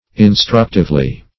-- In*struct"ive*ly, adv. -- In*struct"ive*ness, n.